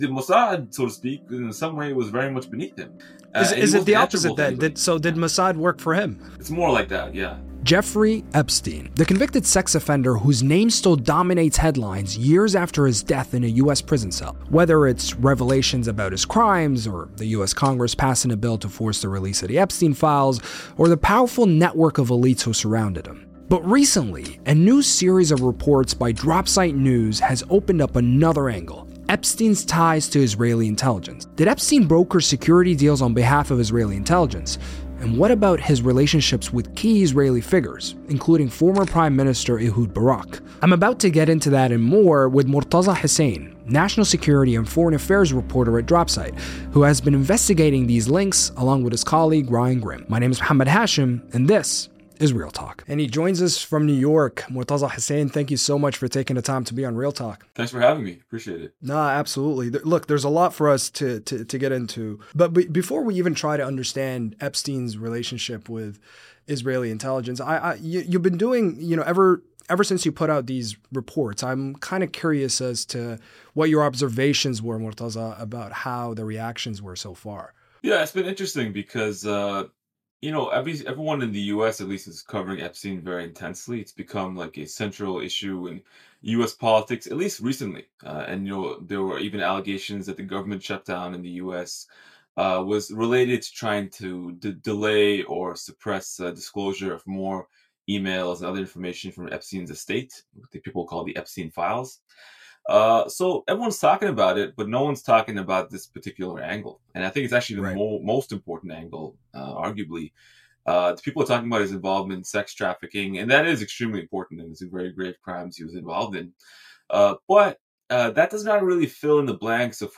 Real Talk is a Middle East Eye interview series